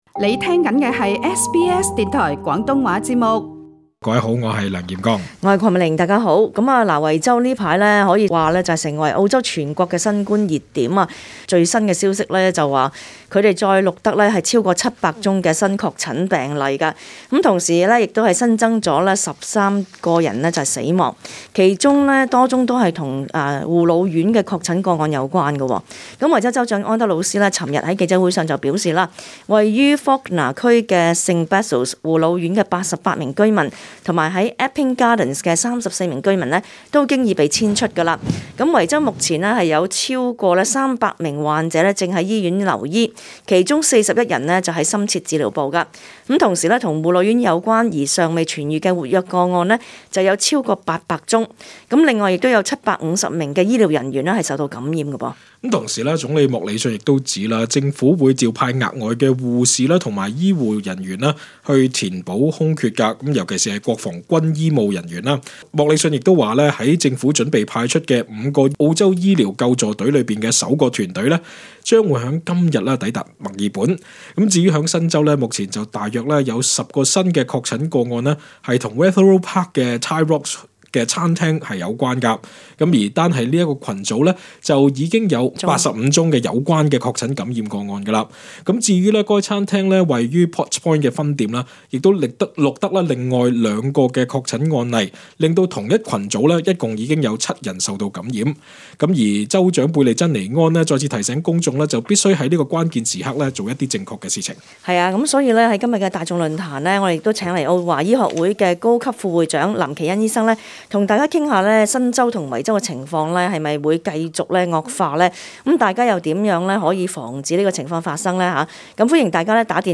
本節目內嘉賓及聽眾意見並不代表本台立場 READ MORE 【想移民？